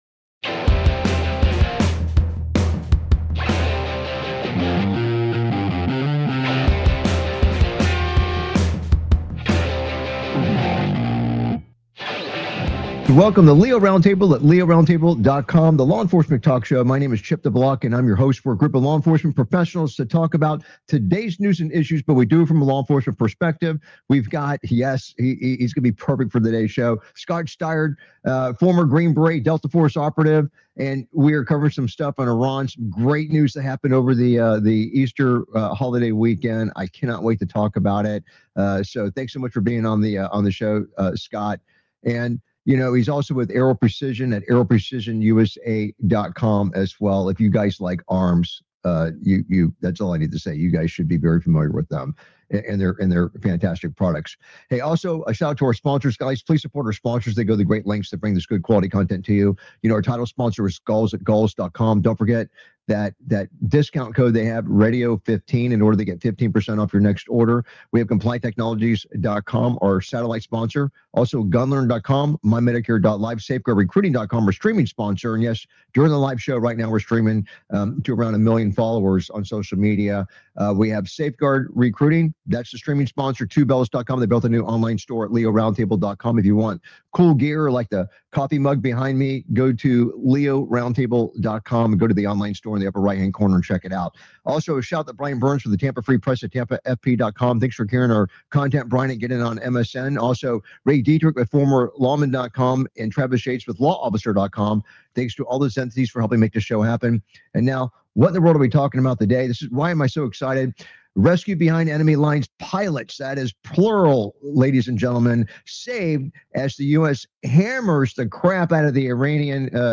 Talk Show Episode, Audio Podcast, LEO Round Table and S11E068, Last Pilot Saved In Iran Mountains Thanks To Precise And Swift Operation on , show guests , about Last Pilot Saved In Iran Mountains,Precise And Swift Operation, categorized as Entertainment,Military,News,Politics & Government,National,World,Society and Culture,Technology,Theory & Conspiracy